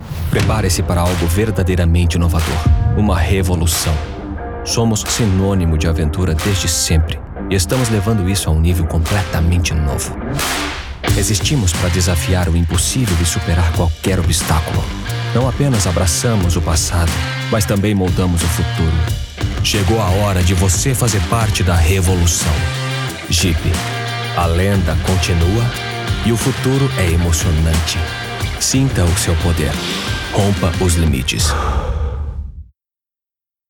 Com uma gama de graves e contralto e configuração de estúdio profissional, ele oferece serviços de locução amigáveis e especializados para marcas que buscam clareza e autenticidade.
Automotivo
Focusrite Scarlet Solo + microfone Akg c3000
GravesContralto
DinâmicoNeutroAmigáveisConversacionalCorporativoVersátil